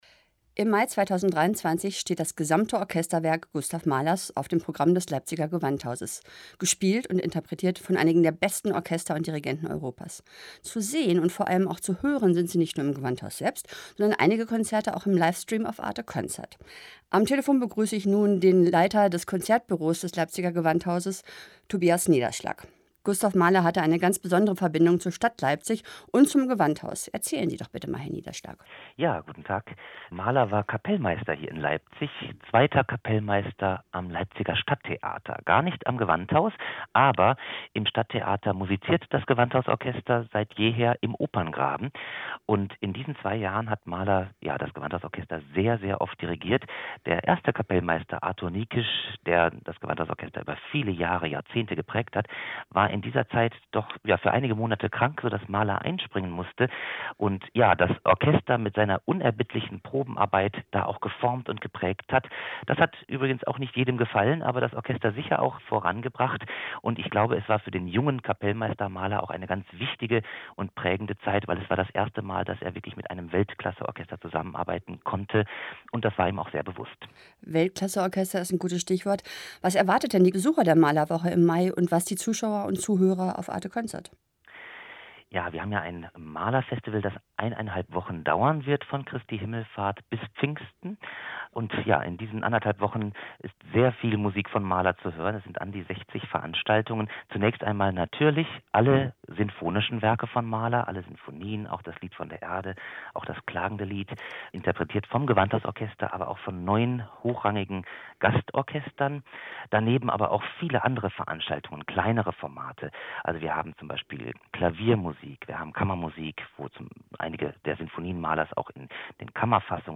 Interview-Mahler-Festival.mp3